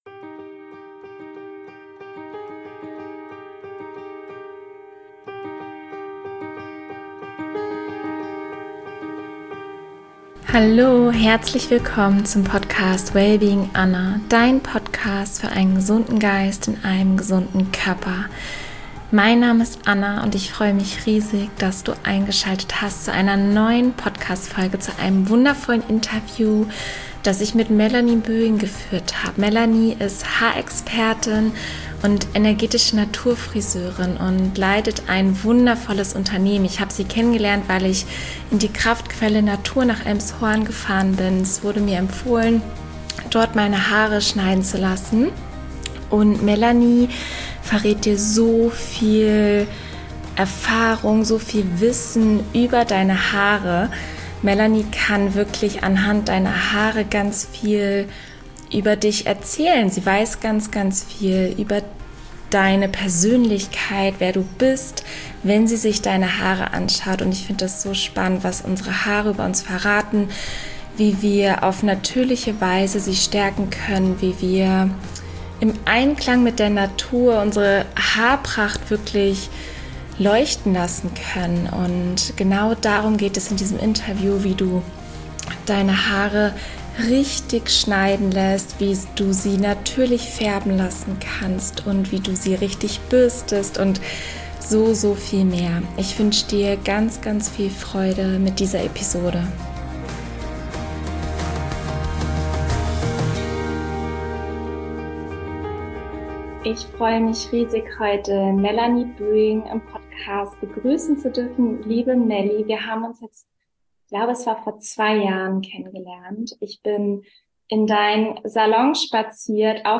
In diesem Interview erfährst du: